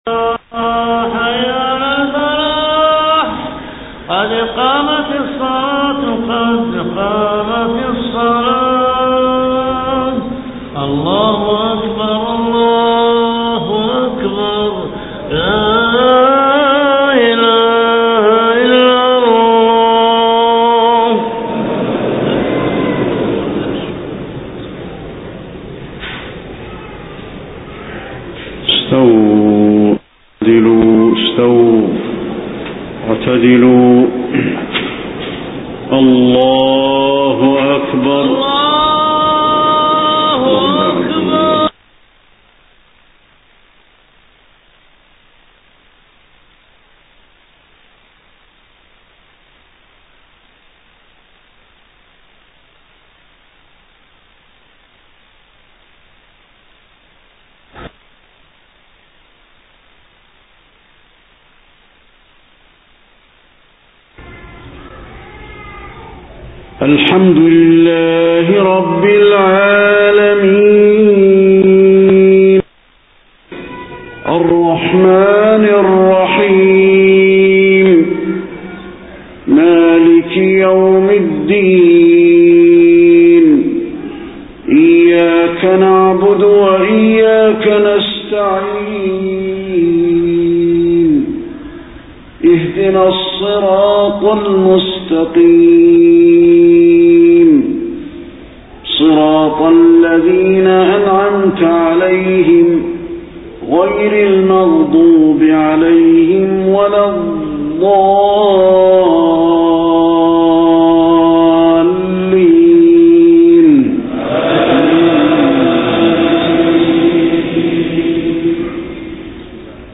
صلاة العشاء 13 ربيع الأول 1431هـ سورة القيامة كاملة > 1431 🕌 > الفروض - تلاوات الحرمين